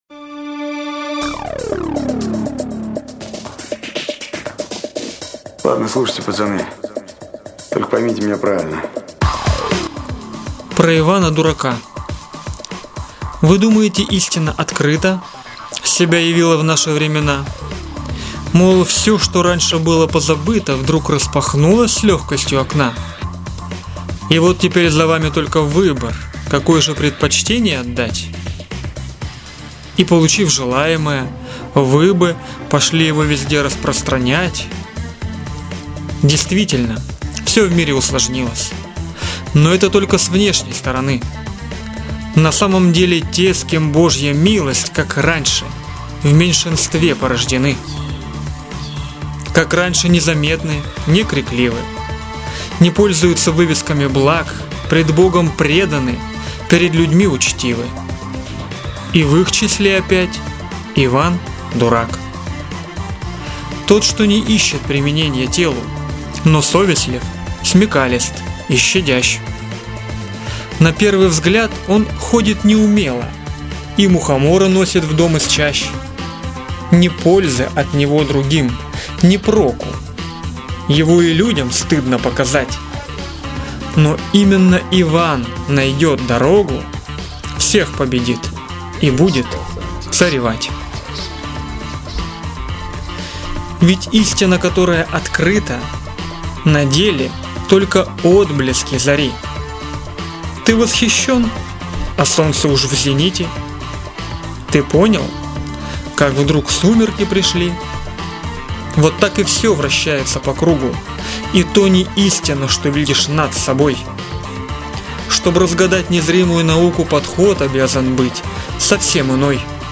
(под ремейк